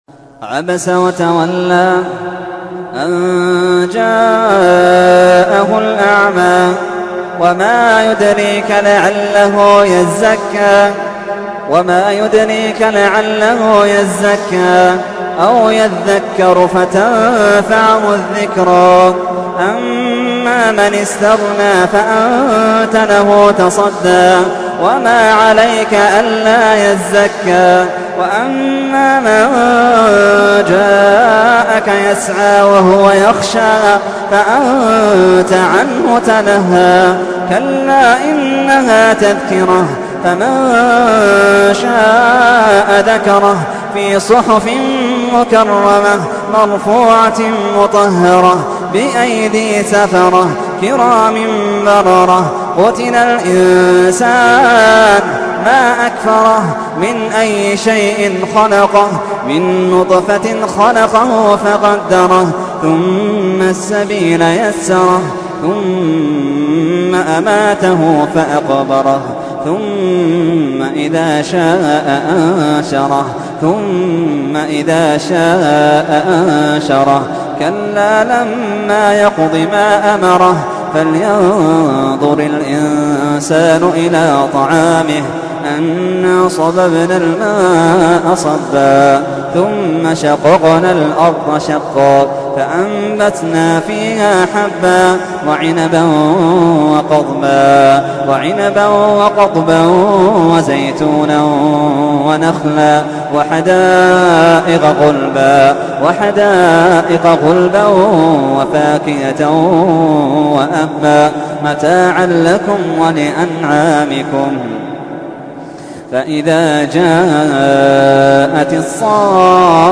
تحميل : 80. سورة عبس / القارئ محمد اللحيدان / القرآن الكريم / موقع يا حسين